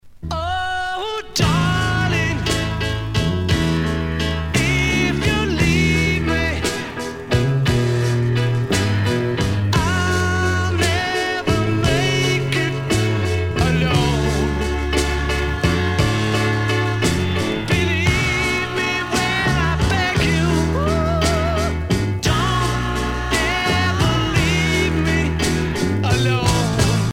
danse : slow